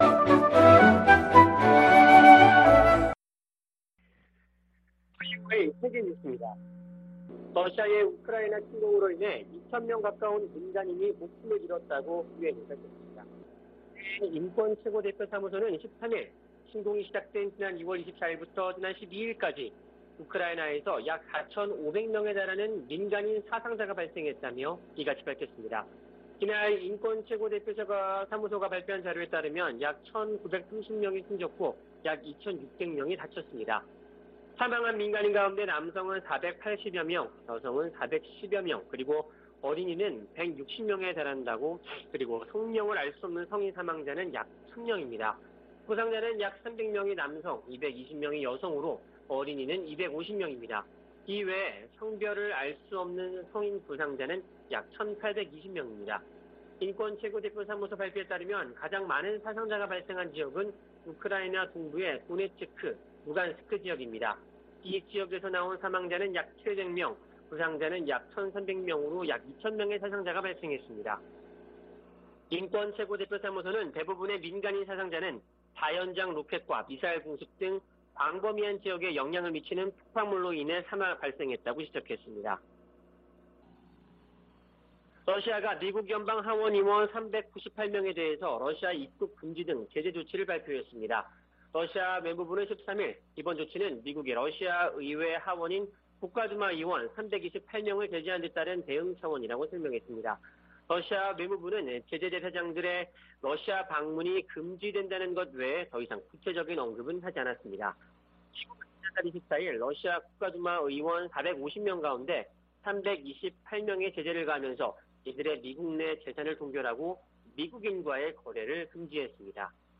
VOA 한국어 아침 뉴스 프로그램 '워싱턴 뉴스 광장' 2022년 4월 15일 방송입니다. 미 7함대는 에이브러햄 링컨 항모가 동해에서 일본 자위대와 연합훈련을 실시하고 있다며 북한에 적대적인 의도는 없다고 밝혔습니다. 미 상·하원이 중국 견제 법안에 관한 조율 절차에 들어갔습니다.